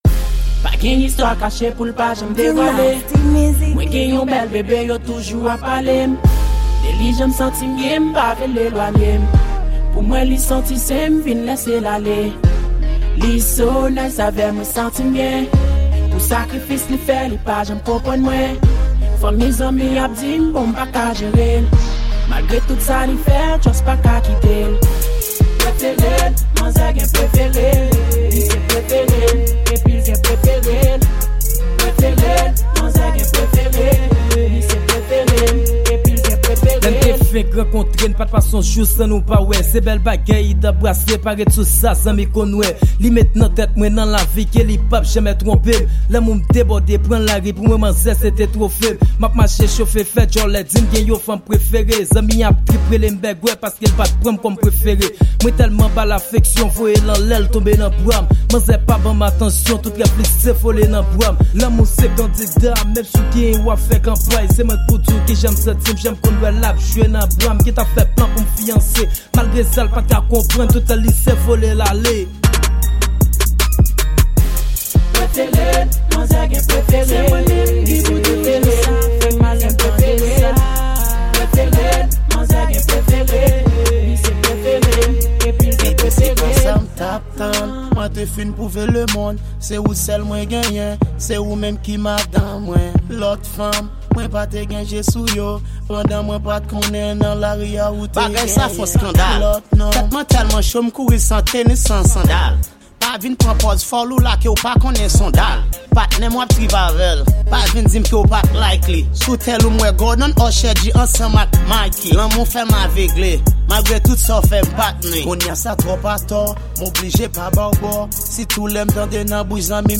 Genre: Rap Kreyol.